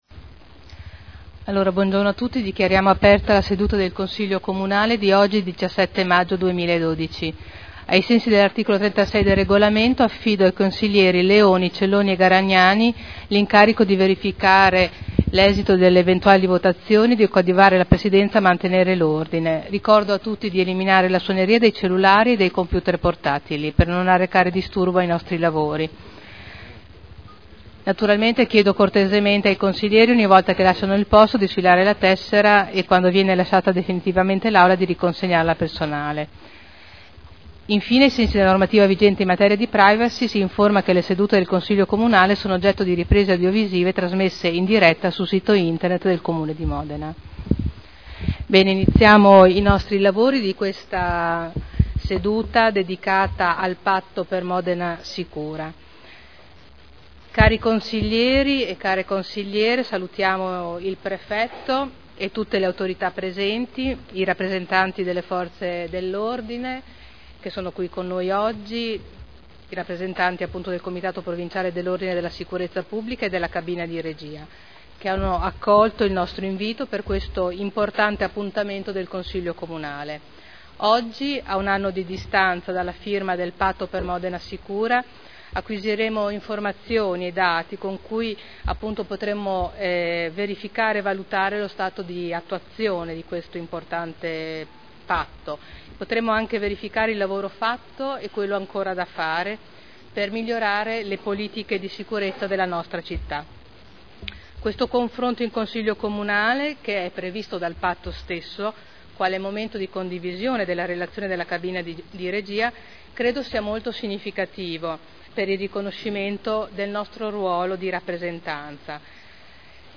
La Presidente Caterina Liotti apre il lavori del Consiglio. La seduta e dedicata al tema "Patto per Modena sicura"